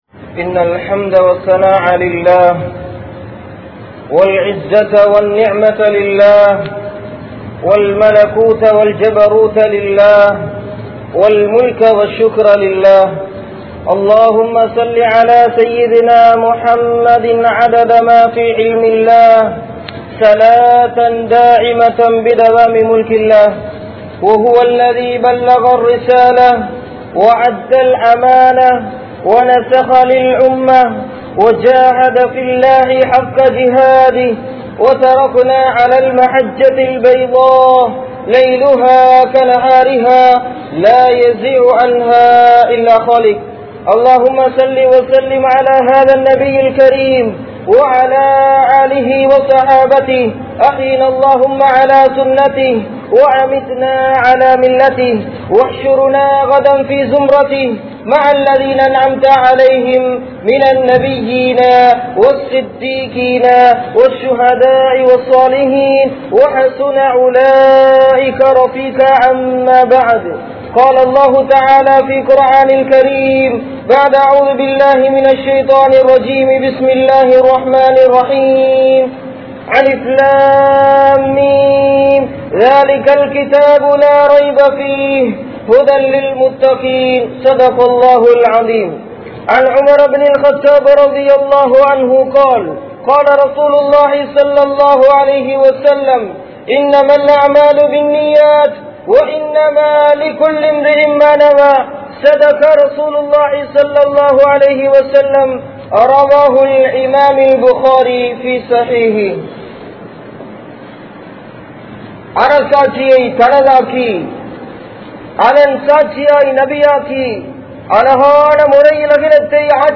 Poien Vilaivuhal (பொய்யின் விளைவுகள்) | Audio Bayans | All Ceylon Muslim Youth Community | Addalaichenai